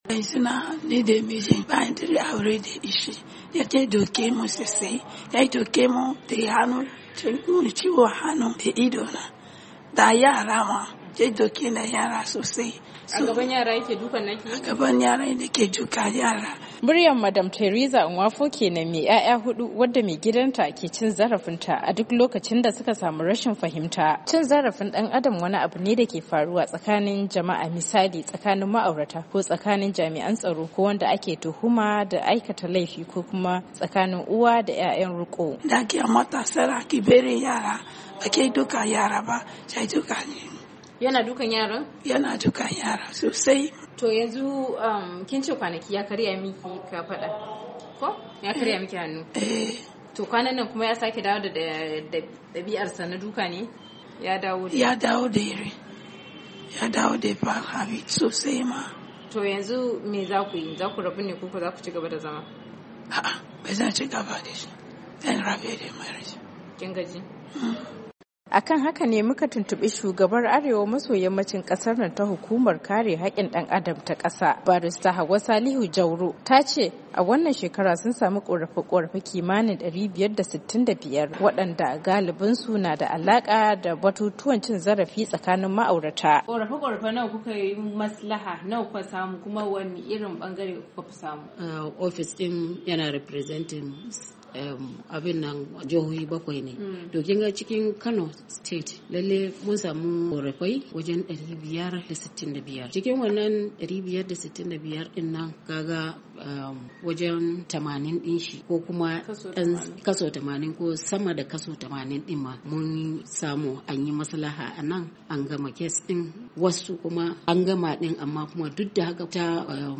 Ga cikkakiyar hirar.